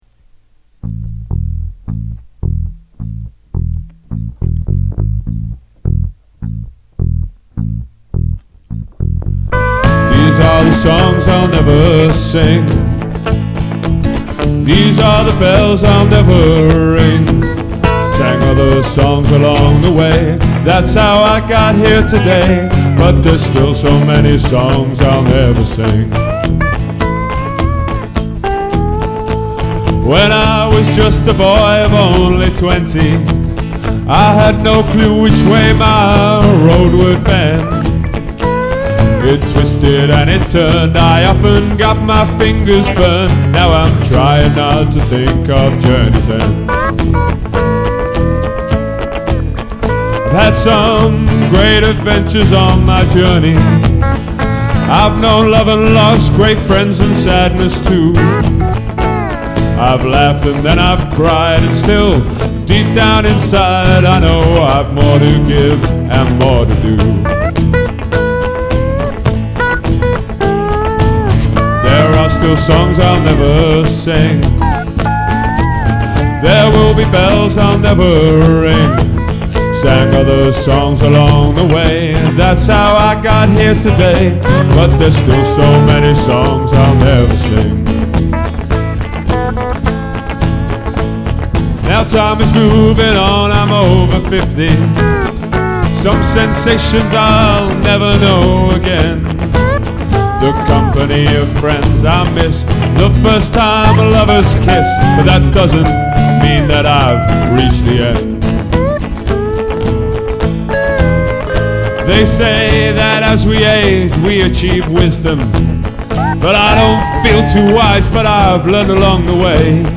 who are a great contemporary punk band.